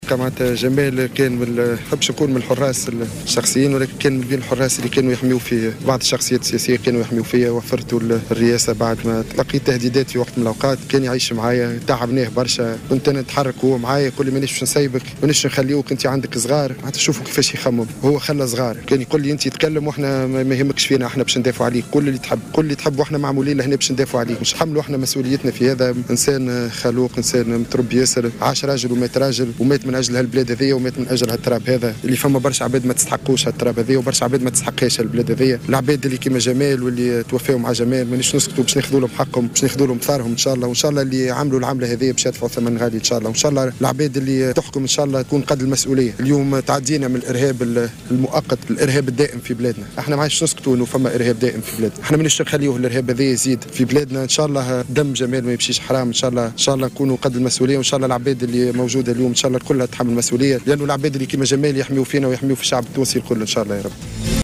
شهادة